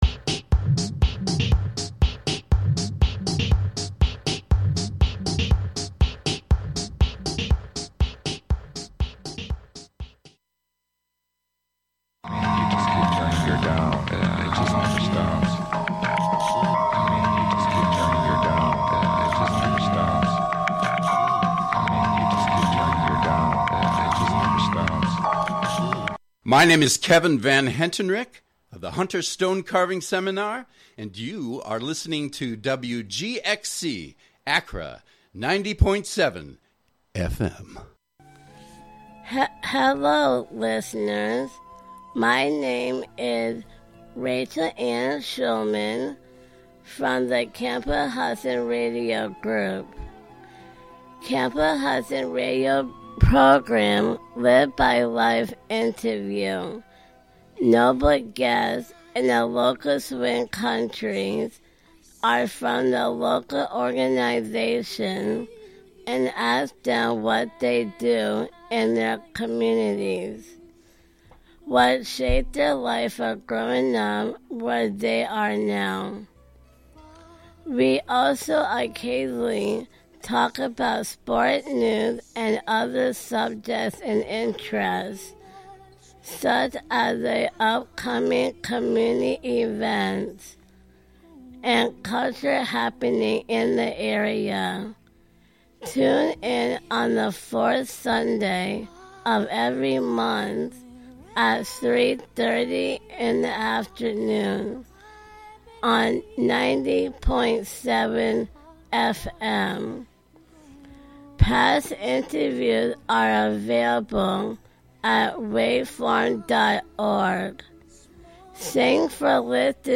Recently built from the dismantled materials of a former emergency vehicle (M49), the unfolded form now serves as a field station constructed from the bones and artifacts of the truck. Tune in to this quarterly broadcast composed of local sound ecology, observational narration, and articulations of the mechanical components of the M49; its meanings and purpose reshaped as it traversed the machine of the road to arrive at its site in the forest of Wave Farm.